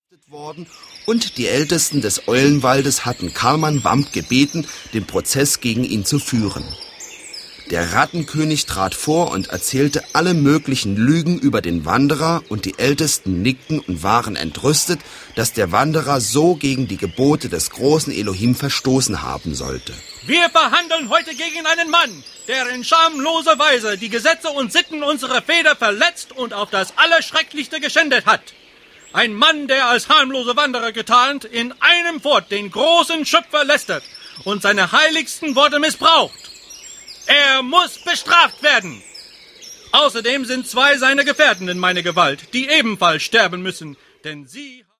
• Sachgebiet: Hörspiele